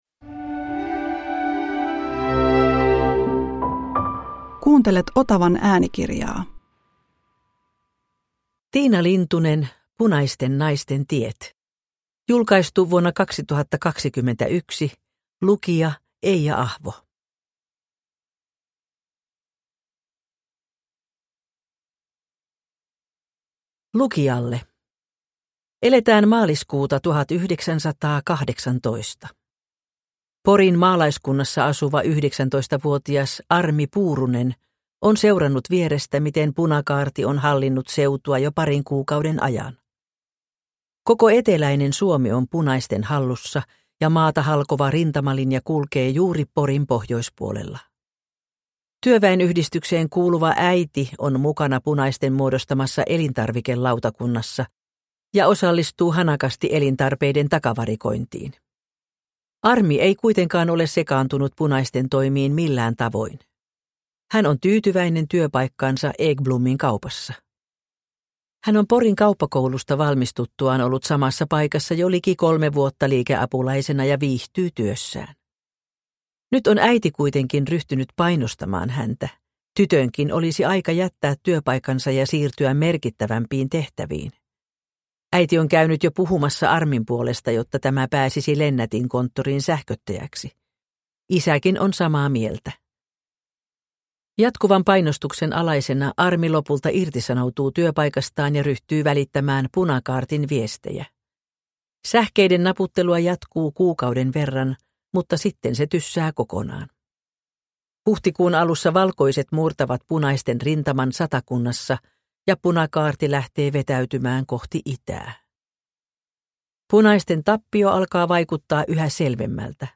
Punaisten naisten tiet – Ljudbok – Laddas ner